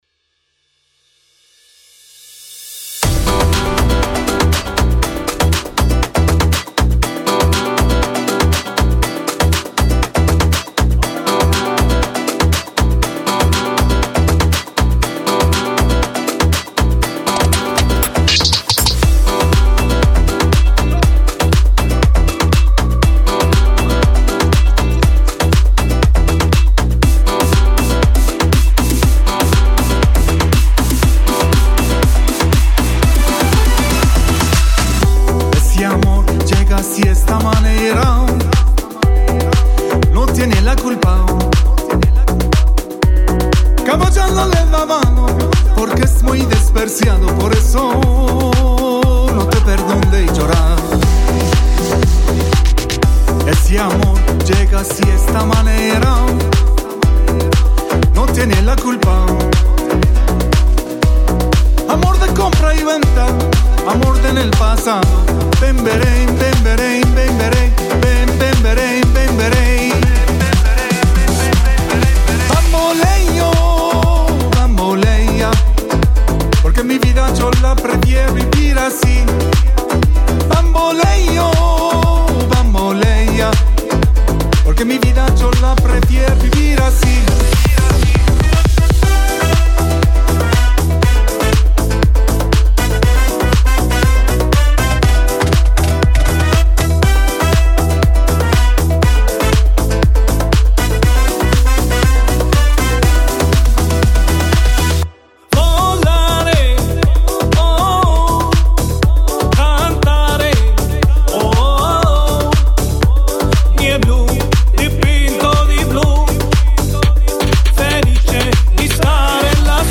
اولین موسیقی با آواز